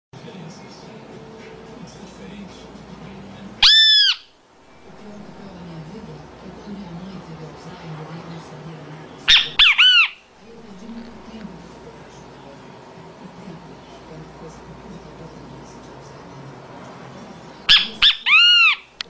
Bem-te-vi (Pitangus sulphuratus)
Nome em Inglês: Great Kiskadee
Fase da vida: Adulto
Localidade ou área protegida: Manuel B. Gonnet
Condição: Selvagem
Certeza: Observado, Gravado Vocal
Benteveo.Wav..mp3